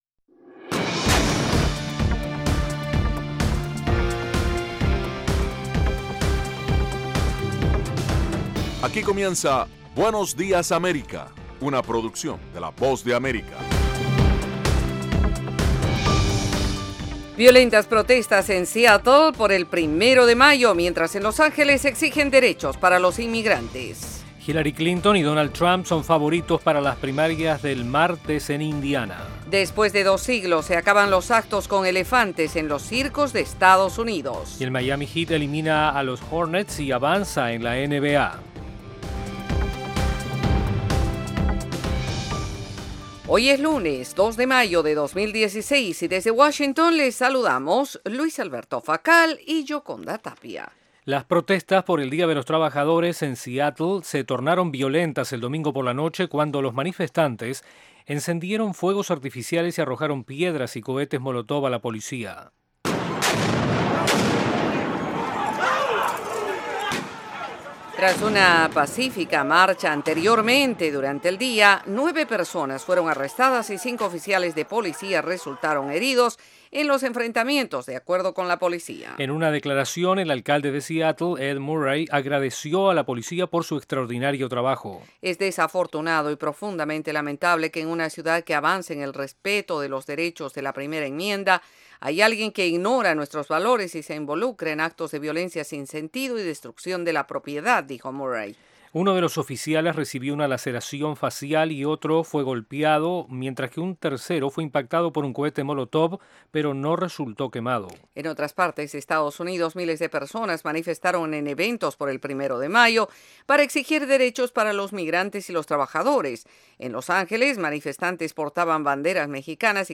Información ágil y actualizada en las voces de los protagonistas con todo lo que sucede en el mundo, los deportes y el entretenimiento.